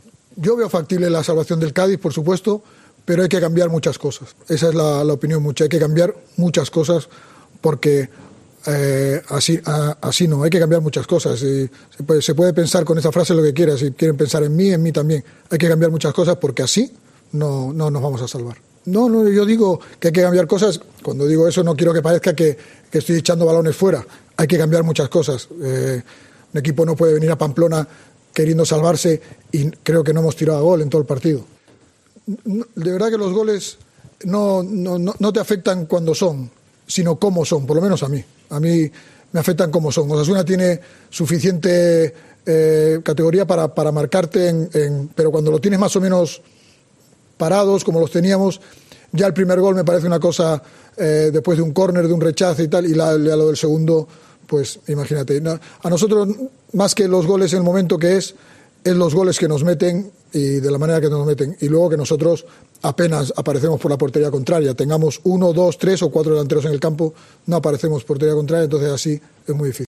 Álvaro Cervera tras el Osasuna 2-0 Cádiz